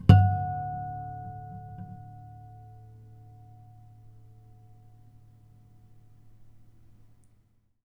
harmonic-08.wav